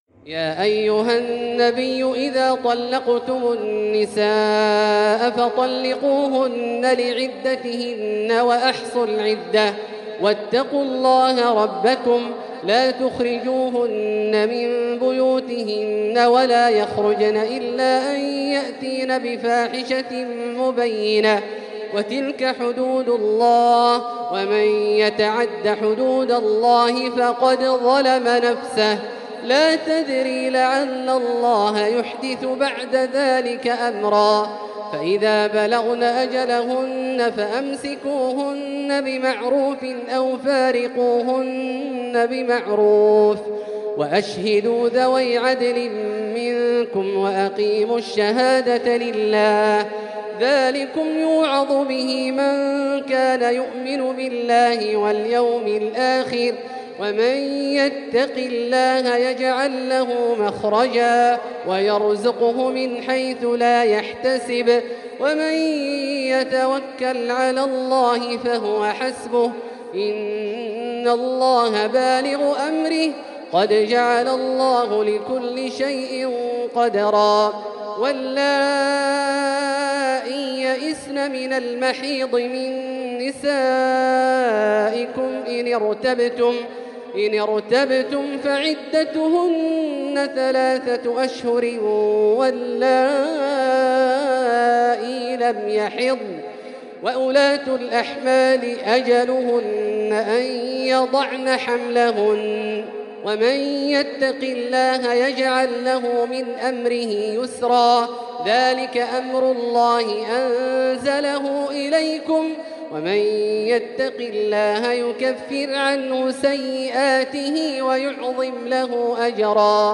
تلاوة مميزة لـ سورة الطلاق كاملة للشيخ أ.د. عبدالله الجهني من المسجد الحرام | Surat At-Talaq > تصوير مرئي للسور الكاملة من المسجد الحرام 🕋 > المزيد - تلاوات عبدالله الجهني